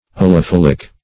Search Result for " allophylic" : The Collaborative International Dictionary of English v.0.48: Allophylic \Al`lo*phyl"ic\, Allophylian \Al`lo*phyl"i*an\, a. [Gr.